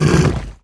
spawners_mobs_uruk_hai_death.3.ogg